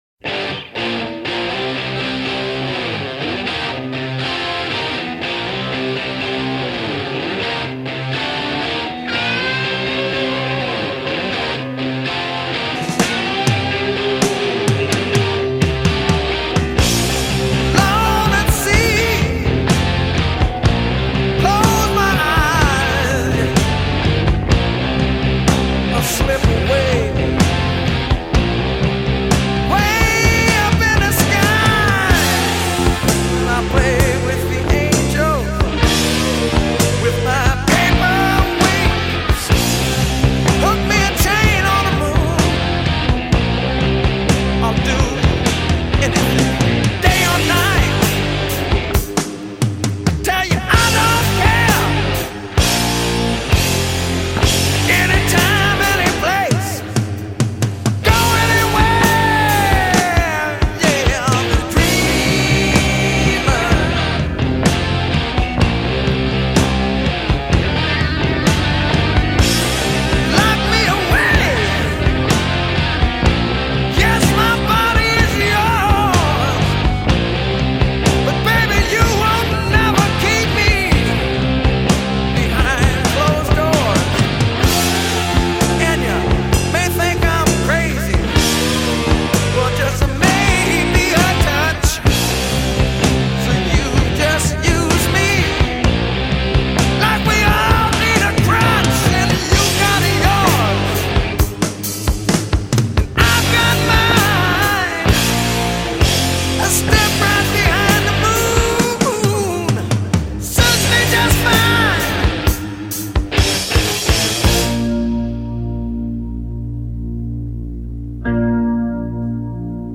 Bass, Synthesizer
Drums
Keyboards, Piano
Keyboards, Mellotron
Guitar
Background Vocals
Lead Vocals